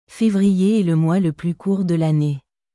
Février est le mois le plus court de l’annéeフェヴリエ エ ル モワ ル プリュ ル クゥール ドゥ ラネ